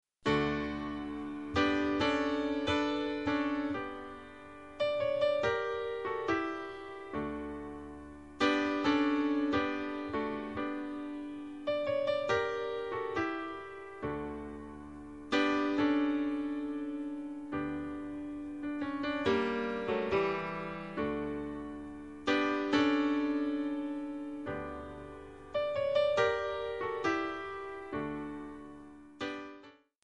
D/Eb
MPEG 1 Layer 3 (Stereo)
Backing track Karaoke
Pop, Musical/Film/TV, Duets, 1980s